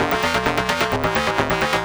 CS_FMArp C_130-E.wav